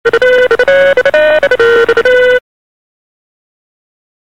Cŕŕn Telefón Austina Powers-a 0:04